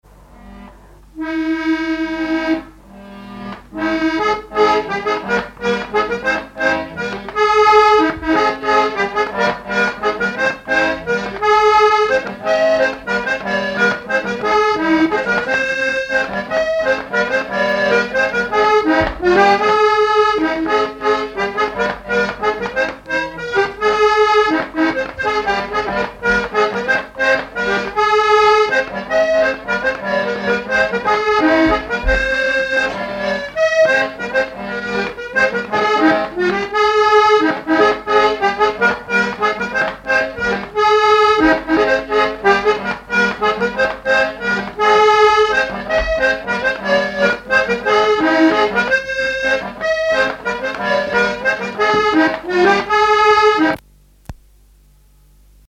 Chants brefs - A danser
danse : mazurka
Pièce musicale inédite